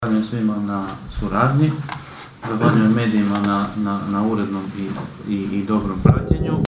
ton – Dean Močinić), kazao je na kraju sjednice općinski načelnik Dean Močinić.